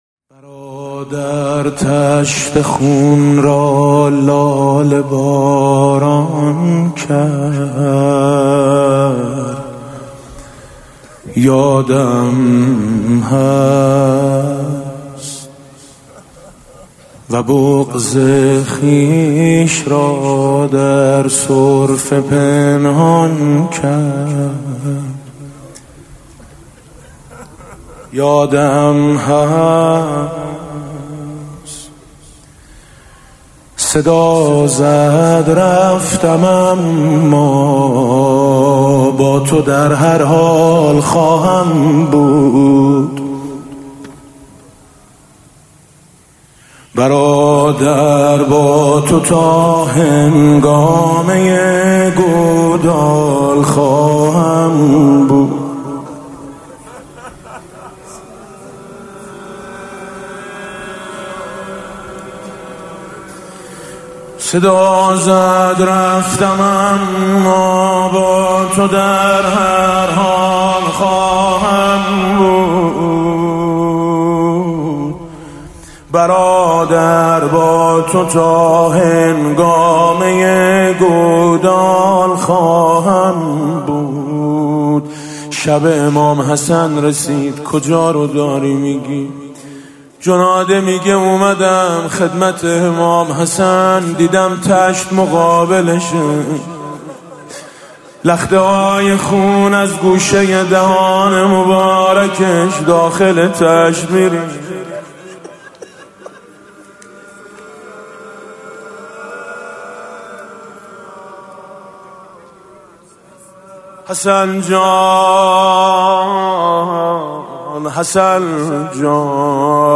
گلچین مداحی میثم مطیعی در شب پنجم ماه محرم منتشر شد.
به گزارش خبرنگار فرهنگی خبرگزاری تسنیم، صوت مداحی میثم مطیعی در شب پنجم ماه محرم 1399 را می‌شنوید:
گلوی سرخ عبدالله آهنگ حسن دارد (روضه)
(زمزمه)
(شور) تشکر رهبر انقلاب از رعایت اصول بهداشتی در عزاداری‌ها انتهای پیام/